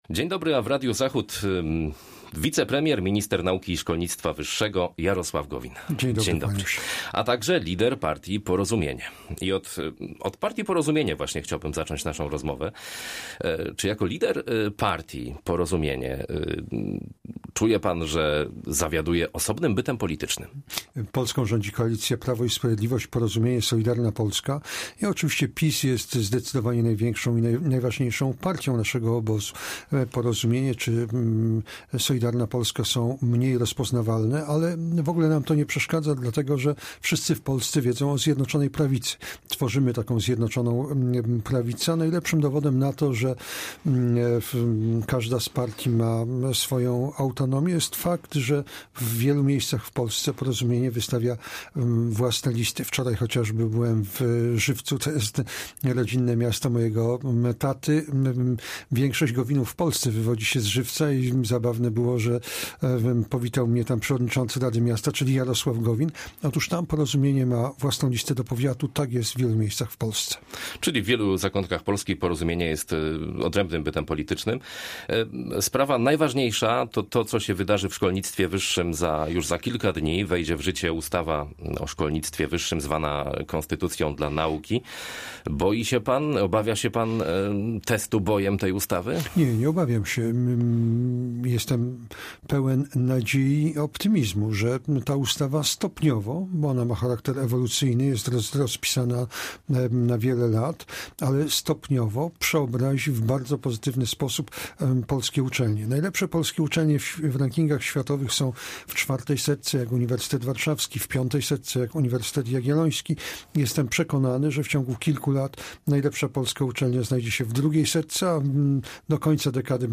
Z wicepremierem, ministrem nauki i szkolnictwa wyższego rozmawia